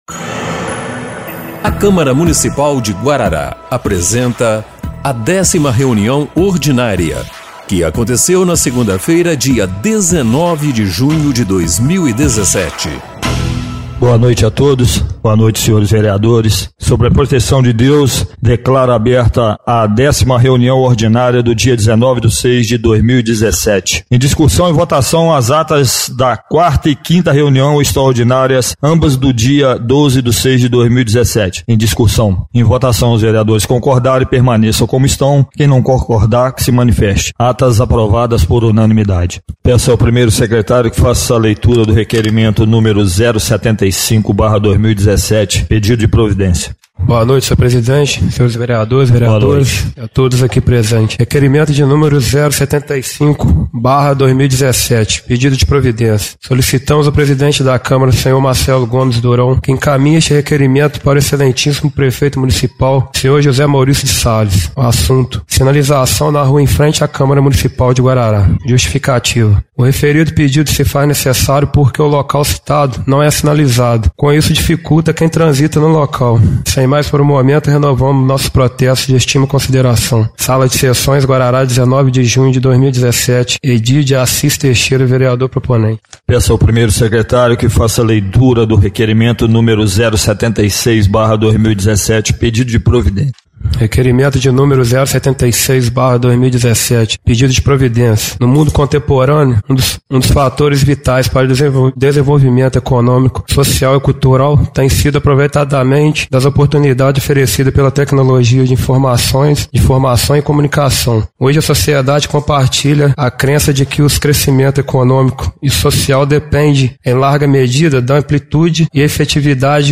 10ª Reunião Ordinária de 19/06/2017